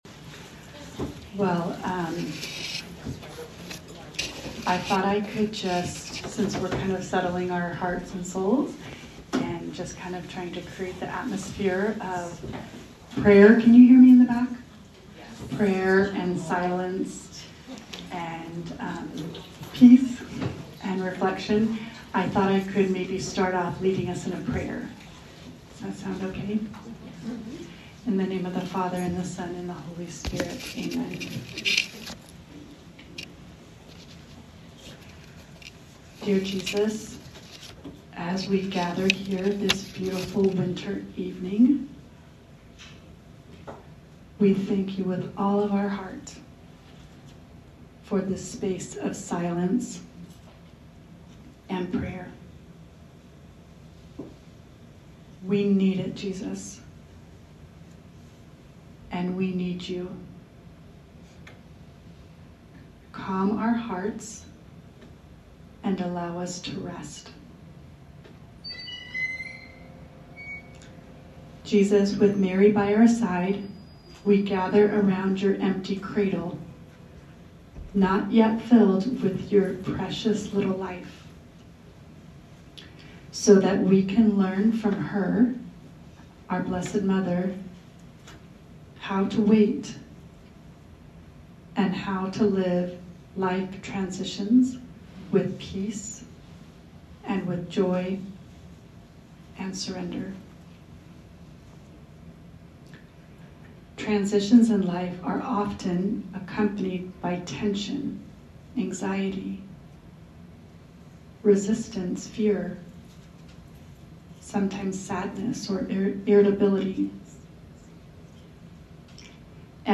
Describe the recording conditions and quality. Advent by Candlelight